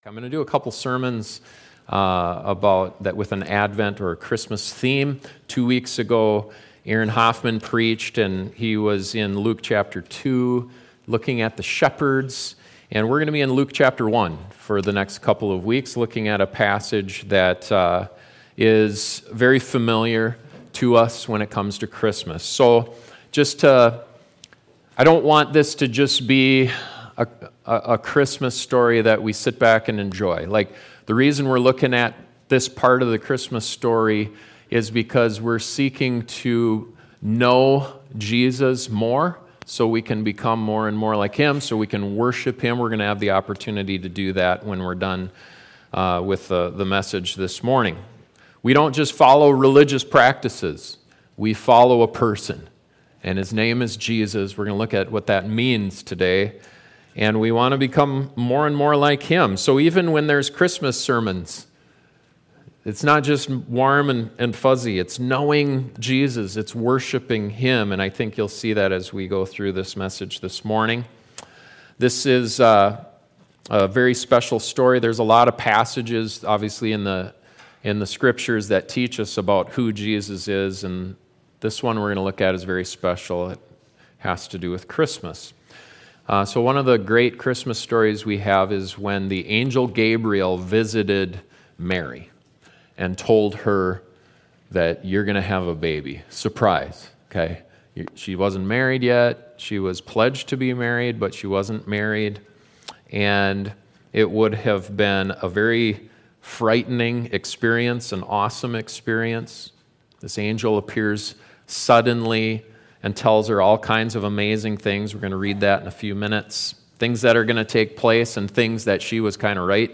This sermon discovers that meaning and fills our hearts with wonder as we celebrate our Savior’s birth.